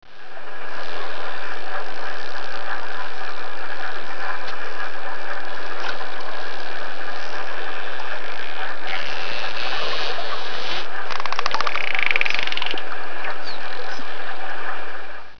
Grand dauphin
Tursiops truncatus
Les cris que vous entendez sont ceux de grands dauphins.
Grand dauphin.wav